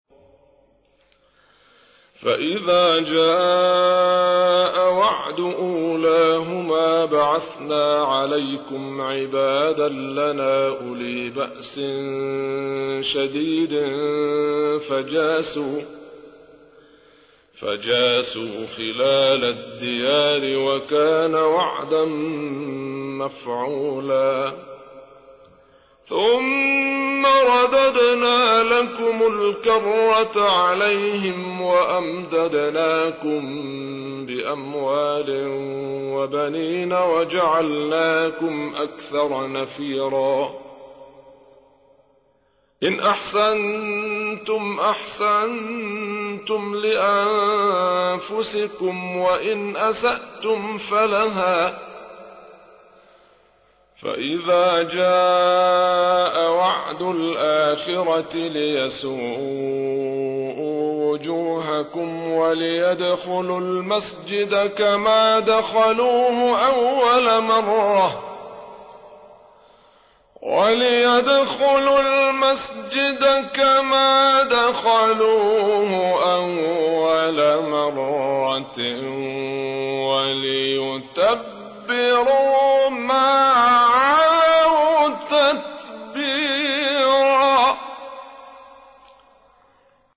گروه فعالیت‌های قرآنی: فرازهای صوتی از قراء برجسته جهان اسلام را می‌شنوید.
مقطعی از عبدالعزیز حصان در مقام حجاز از سوره انبیا